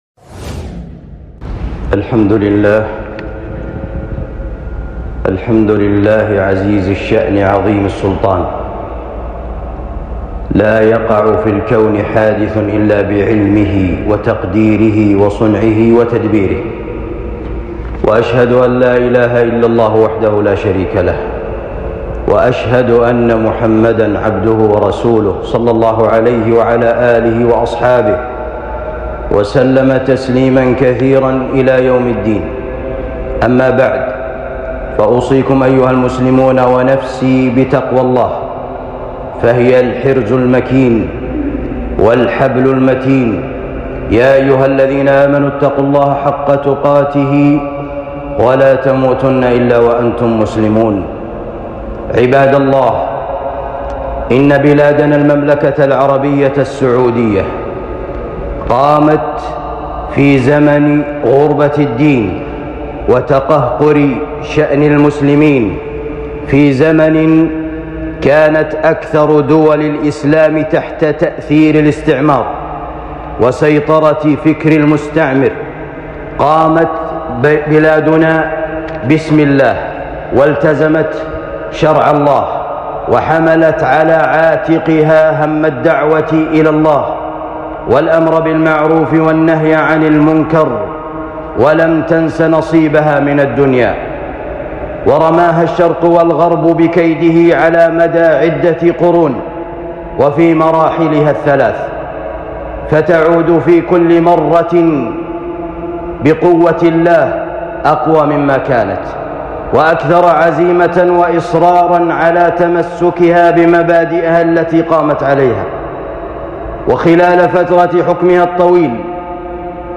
خطبة جمعة بعنوان نعمة التوحيد ووحدة الصف على بلادنا المملكة العربية السعودية